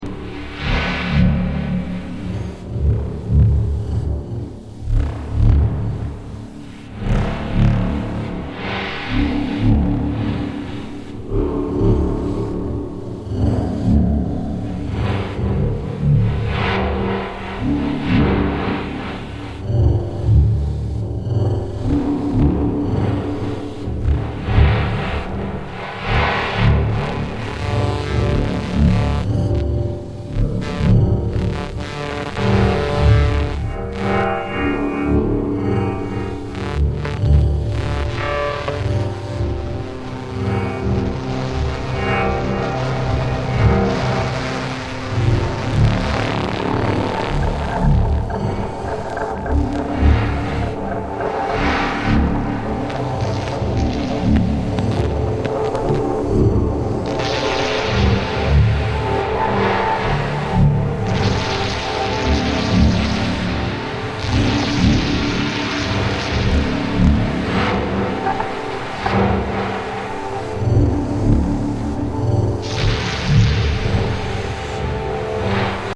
electro-acoustic music